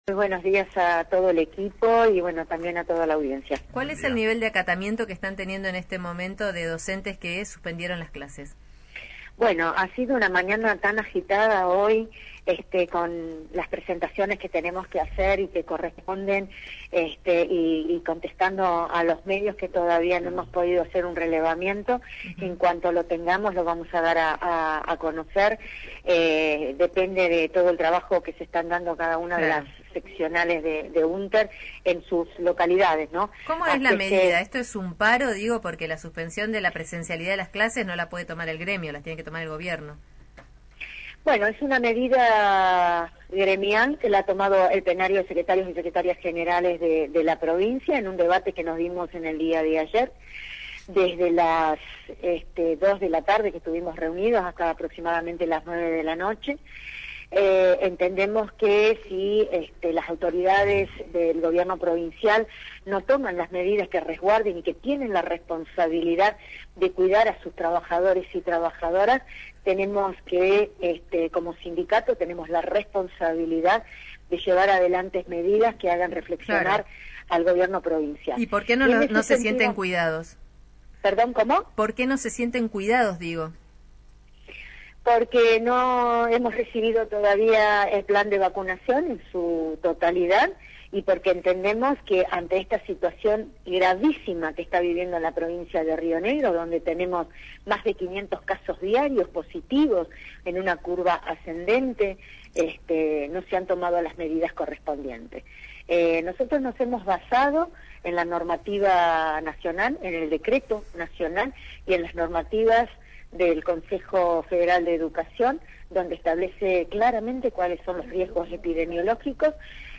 Entrevista radial
Entrevista